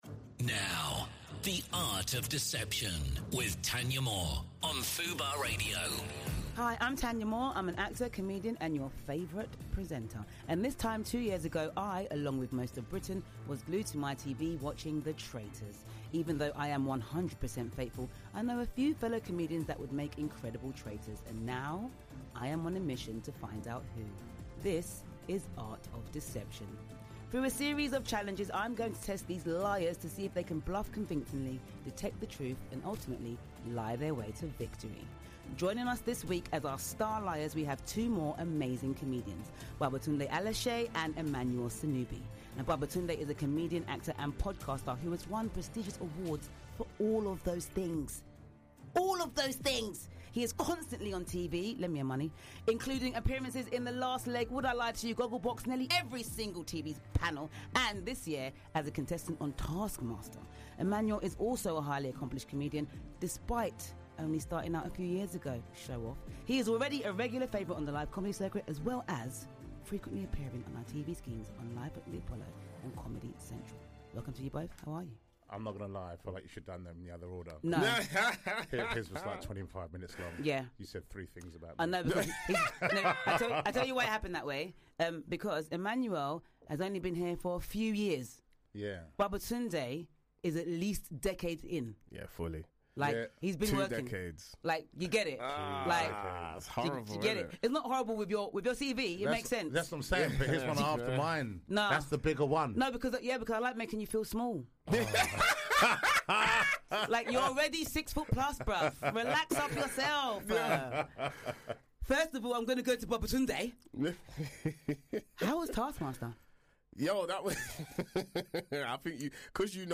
Testing their lying skills with Thanyia today are Babatunde Aléshé and Emmanuel Sonubi.